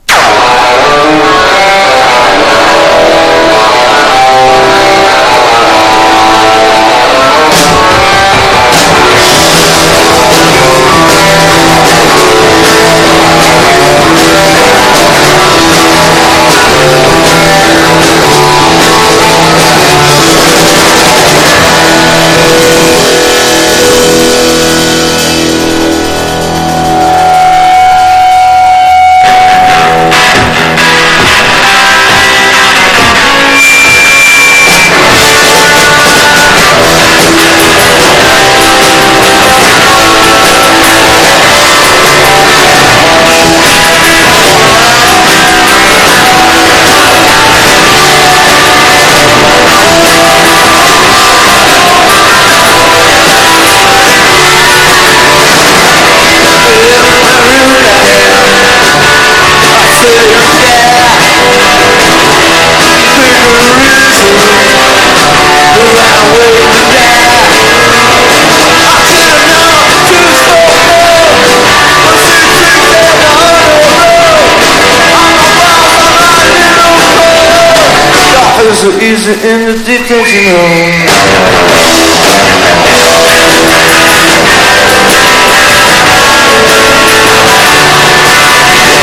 1. 70'S ROCK >
PUNK / HARDCORE